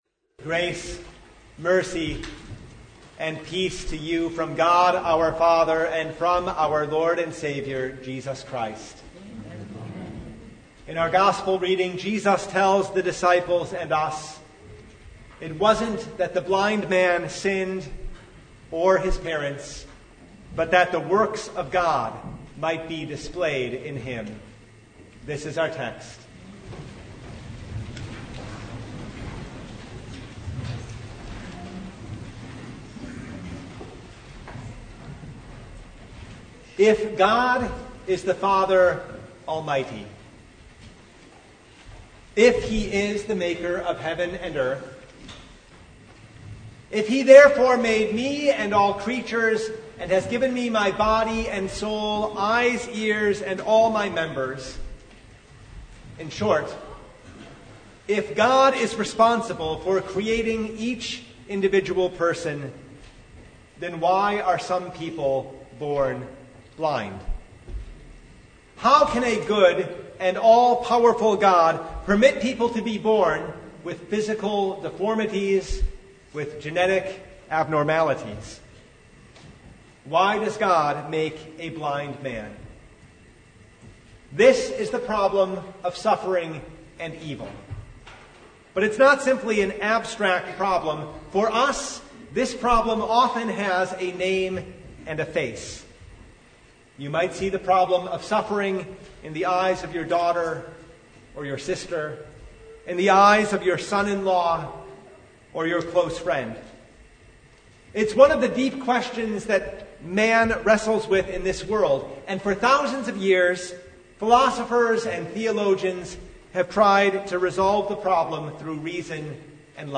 John 9:1-41 Service Type: Sunday What do we do with a man born blind?